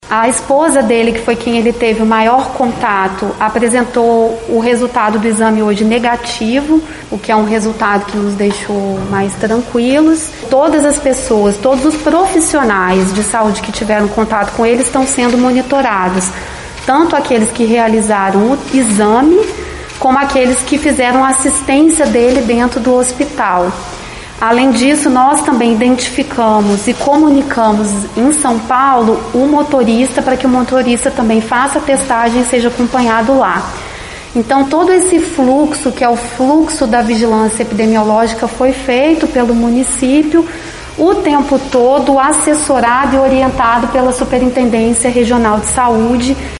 Em coletiva de imprensa na tarde desta sexta-feira, 28, O caso da pessoa internada, em Juiz de Fora, com a cepa indiana do novo coronavírus foi explicado pela Secretaria Municipal de Saúde e pela Superintendência Regional de Saúde de Juiz de Fora (SRS-JF).
28.05_Coletiva-cepa-indiana-JF_Sec-Saude-Ana-Pimentel-1.mp3